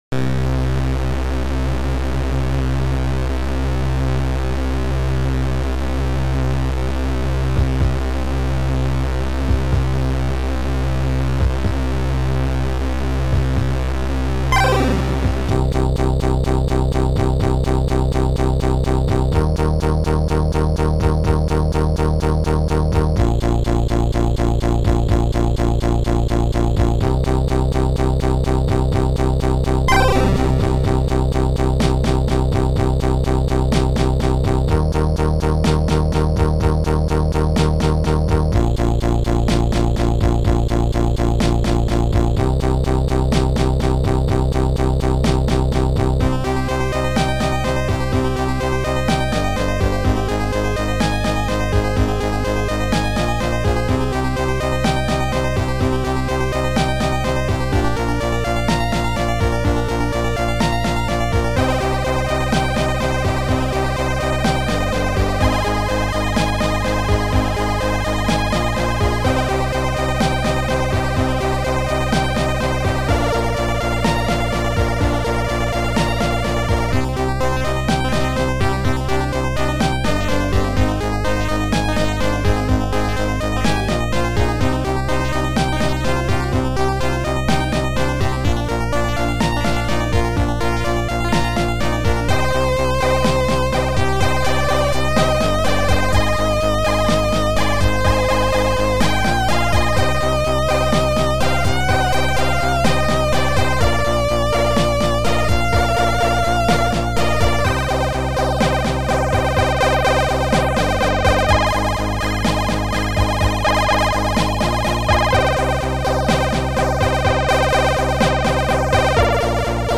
Synth
Future Composer Module